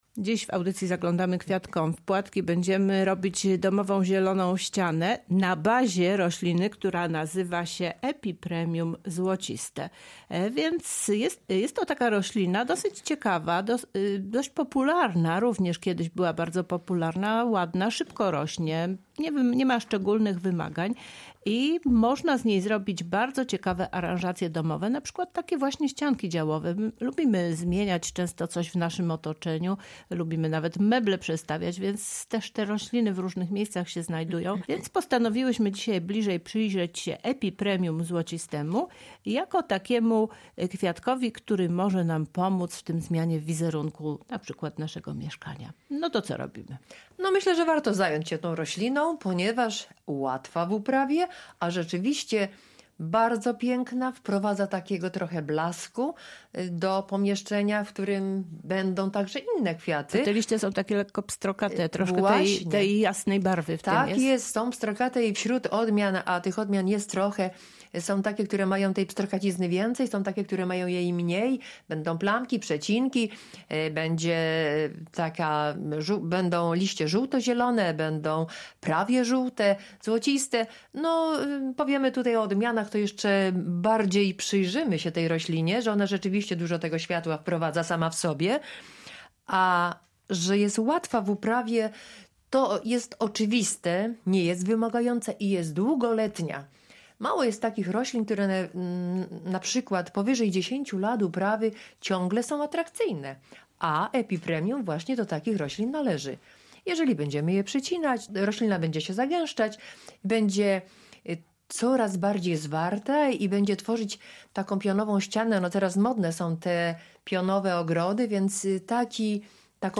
Więcej o tej roślinie w rozmowie